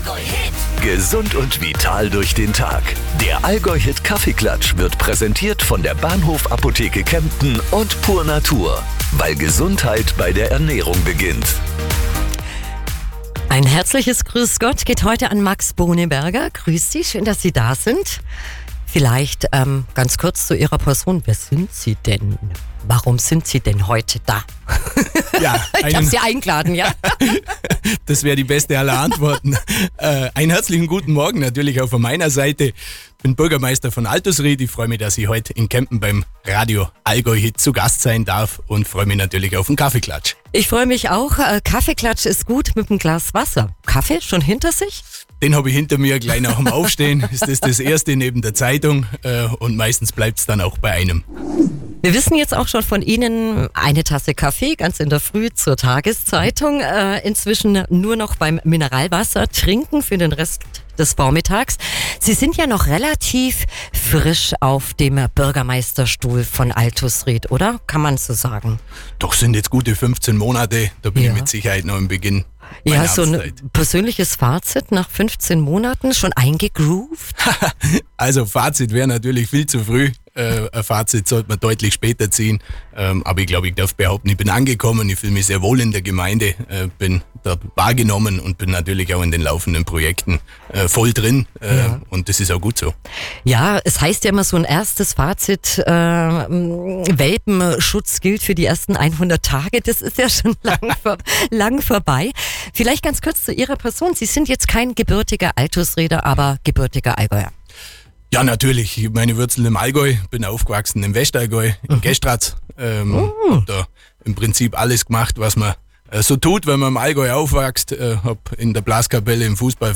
Kaffeeklatsch 05.06.25: Max Boneberger Bürgermeister von Altusried